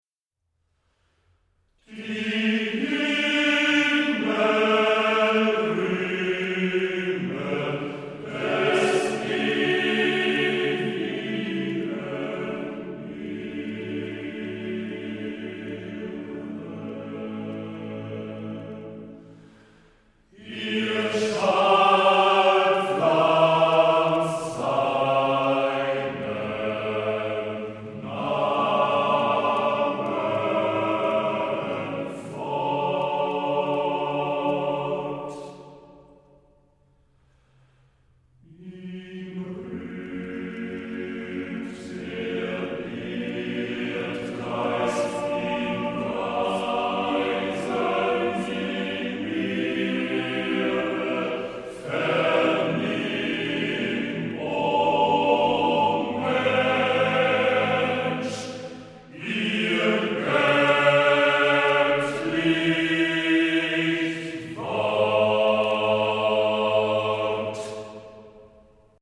DER MÄNNERCHOR